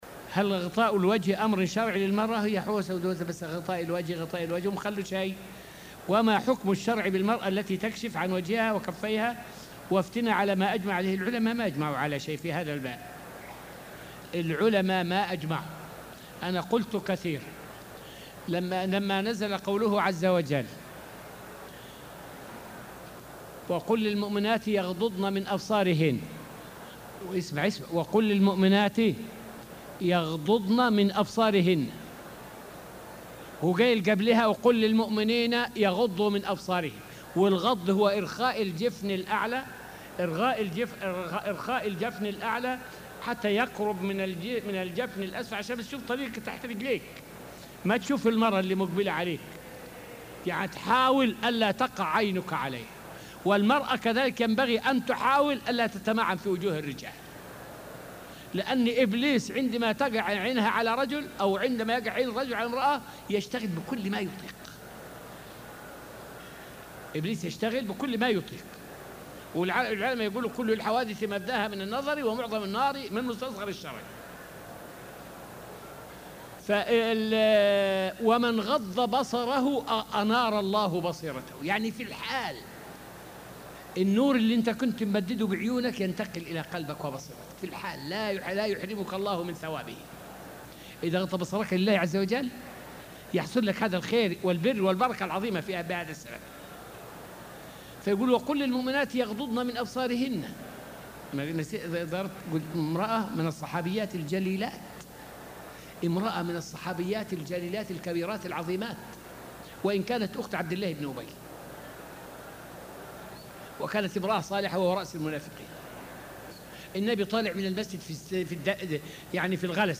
فائدة من الدرس الثلاثون من دروس تفسير سورة البقرة والتي ألقيت في المسجد النبوي الشريف حول معنى التبذل ومتى تتبذل المرأة.